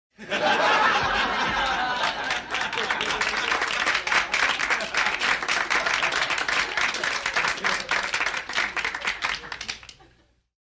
Crowd - Canned laugh, extended